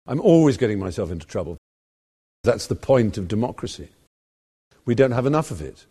Let us Let’s listen to some spoken examples from popular Brits. Stephen Fry: